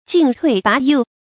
進退跋疐 注音： ㄐㄧㄣˋ ㄊㄨㄟˋ ㄅㄚˊ ㄉㄧˋ 讀音讀法： 意思解釋： 猶言進退兩難。